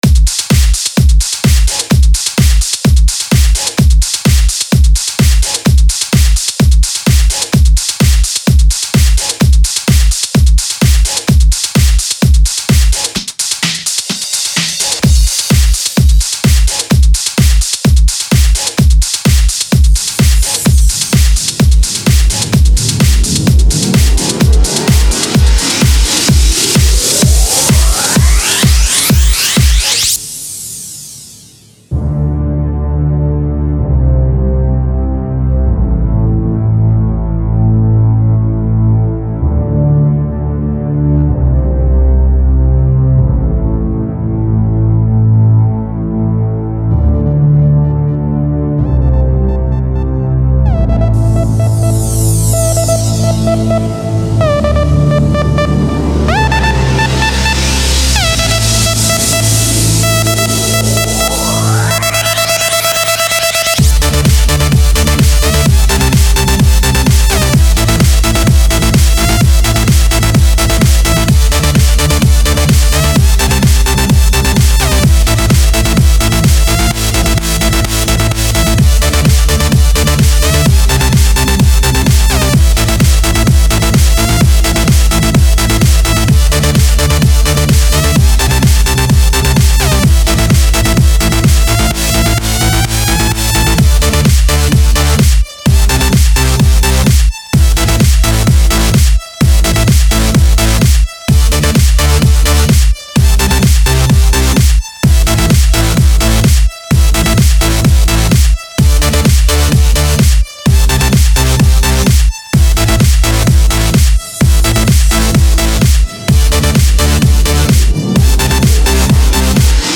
_trance__mix_.mp3